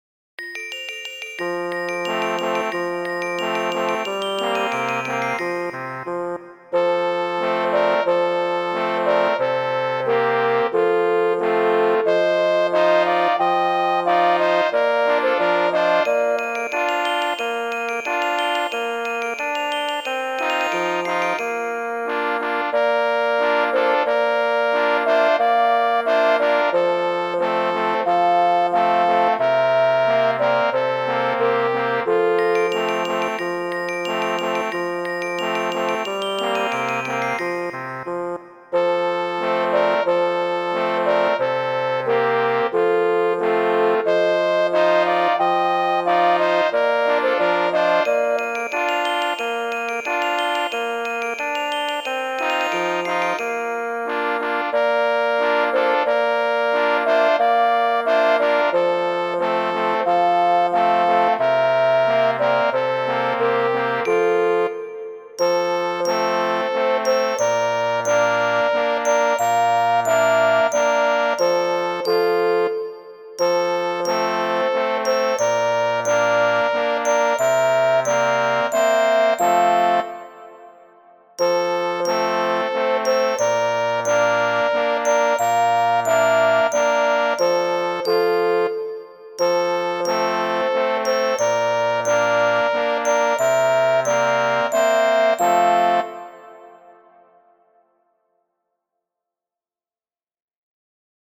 Anoncu, popola kristnaskokanto de Kanariaj Insuloj, kiun mi midiigis.